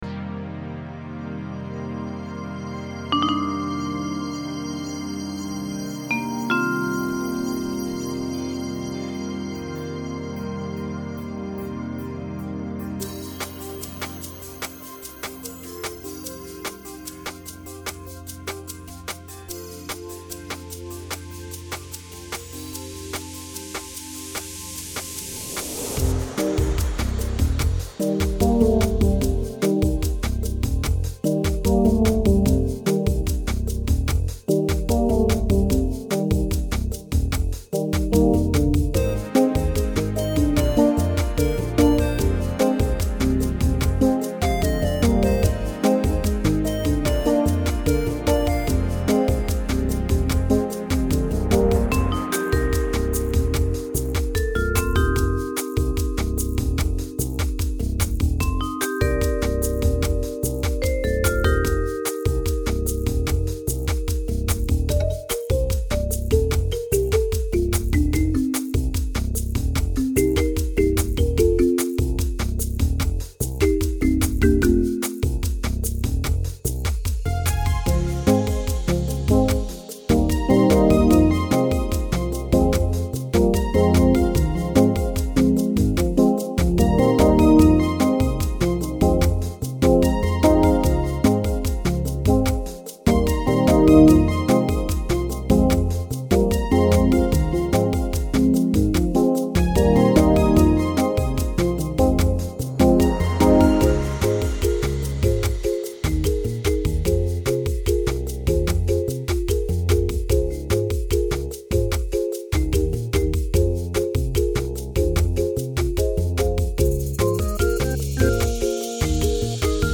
Home > Music > Jazz > Bright > Smooth > Medium